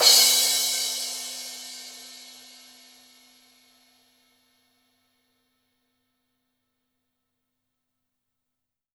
TM-88 Crash #08.wav